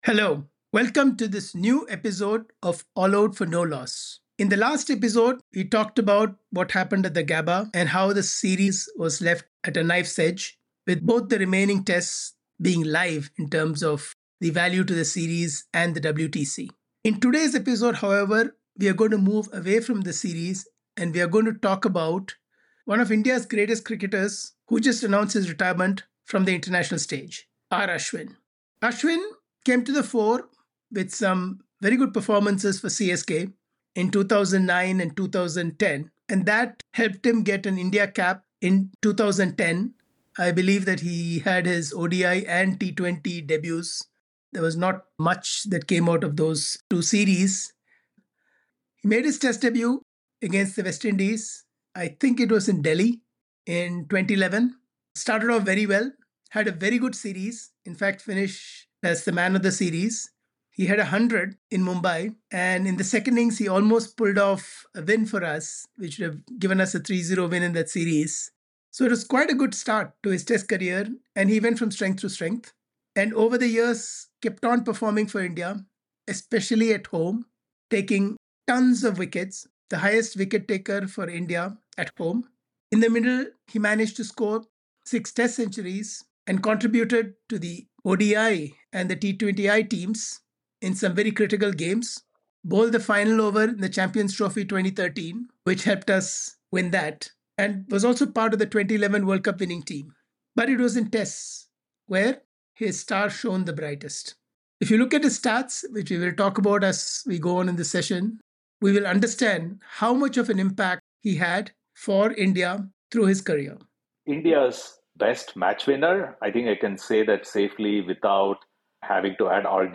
In this episode the full crew of ‘All Out 4 No Loss’ get together and discuss the life and times of R Ashwin as an Indian cricketer.